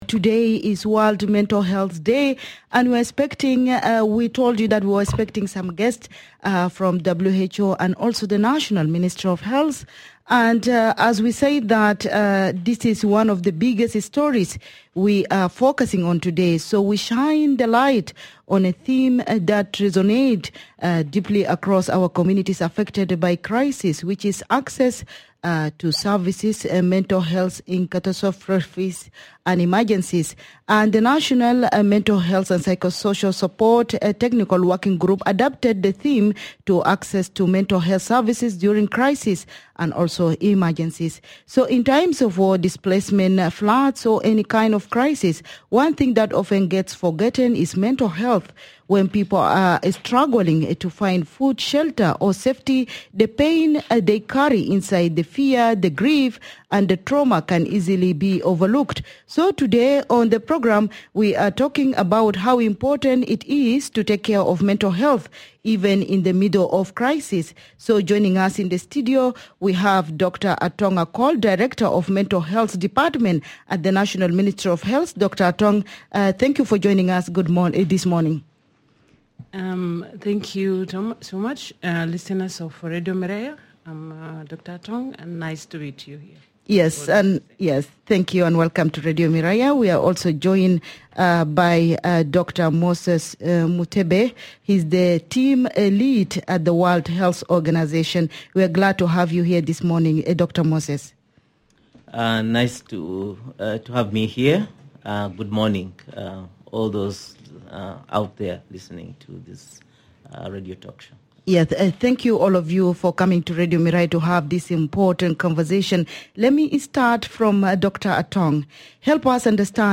In conversation with the Miraya Breakfast Show are: